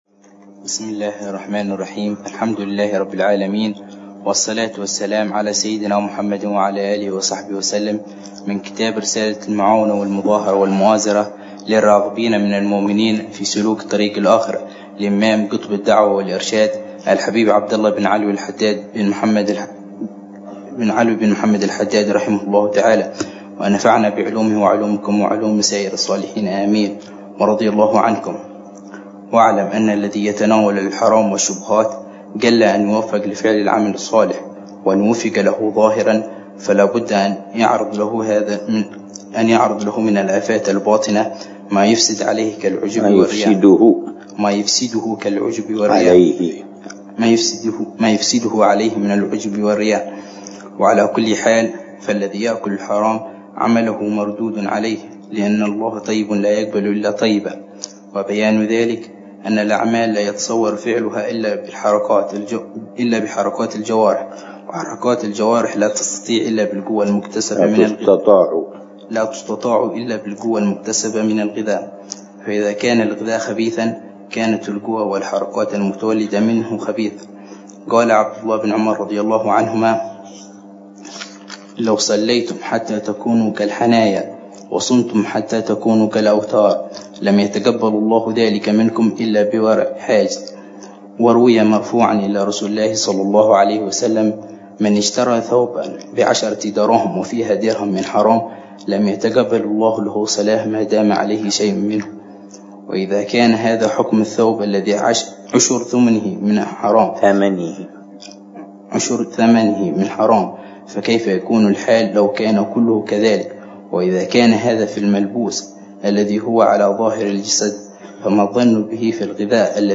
قراءة بتأمل وشرح واقعي لكتاب رسالة المعاونة للإمام عبد الله بن علوي الحداد، يلقيها الحبيب عمر بن محمد بن حفيظ لكبار طلاب حلقات المساجد